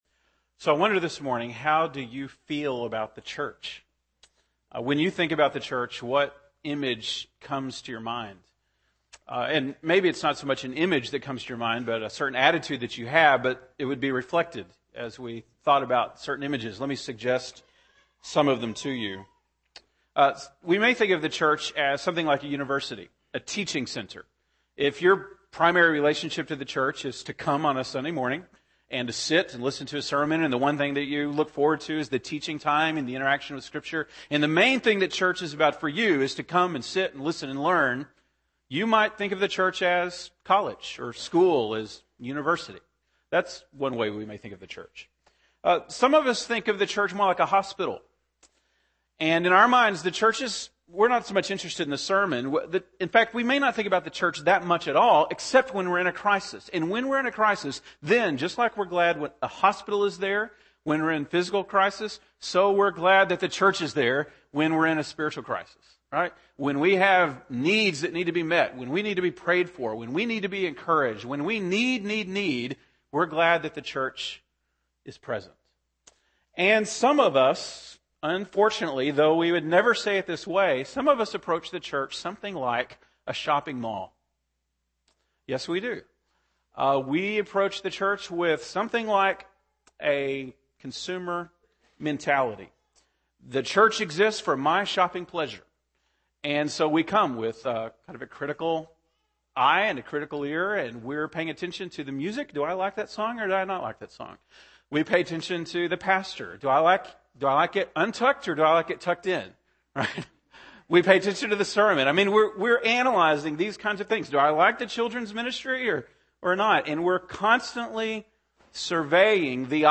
January 16, 2011 (Sunday Morning)